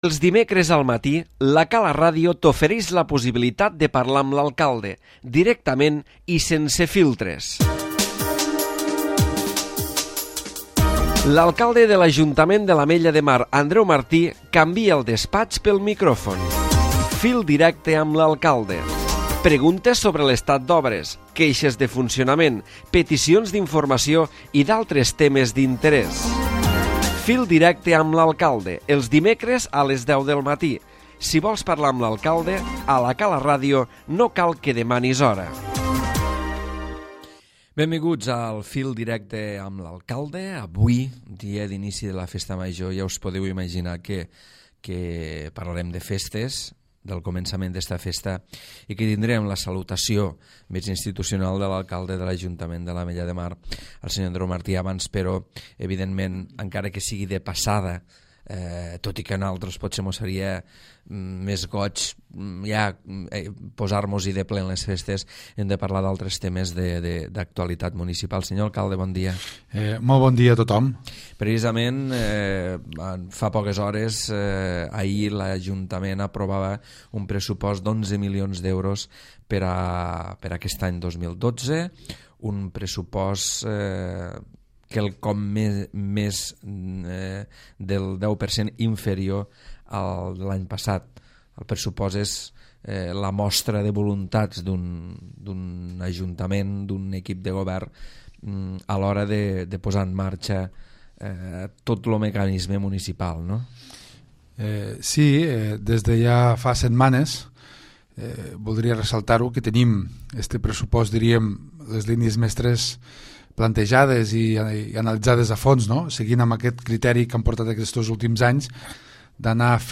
Andreu Martí, Alcalde de l'Ajuntament de l'Ametlla de Mar ha aprofitat el Fil Directe d'avui per a saludar la Festa Major de la Candelera als ciutadans de l'Ametlla de Mar.